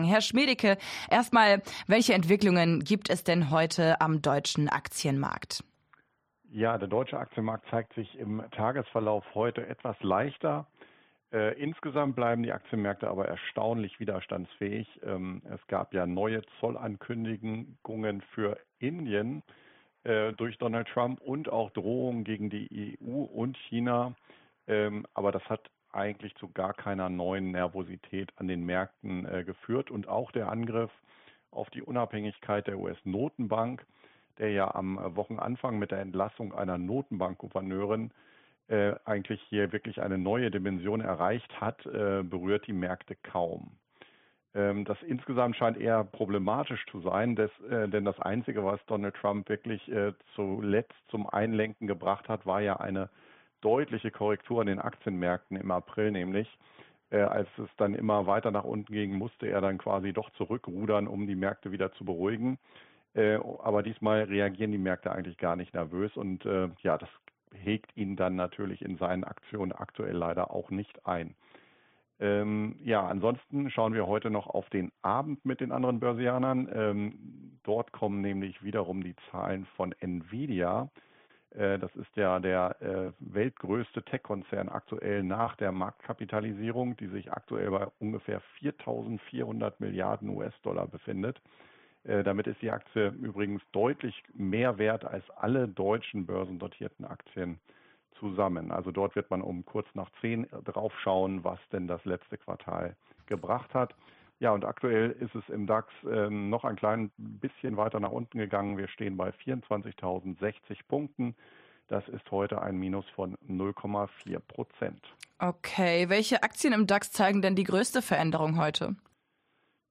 Börsengespräch